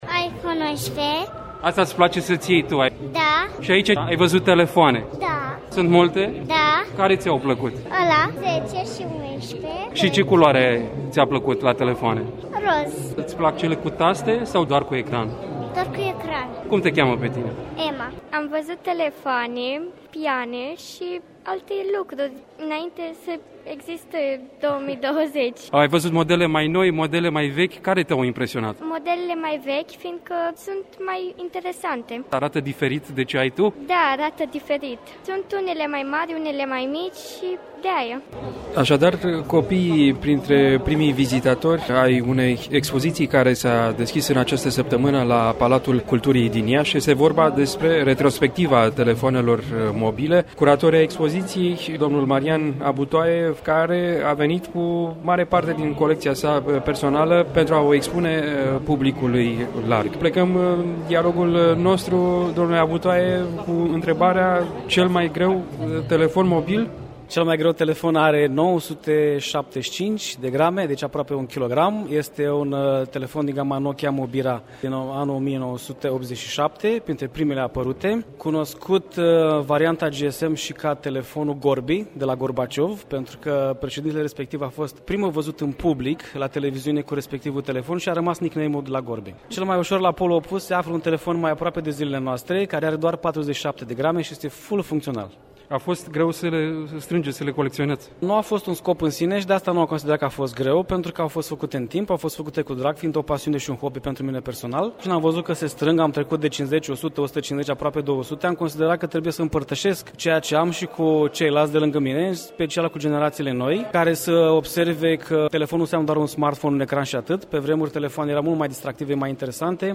La momentul vernisajului, prezente gazdele, dar și vizitatori, printre care, copii de diferite vârste, dar cu aceeași intensitate a curiozității.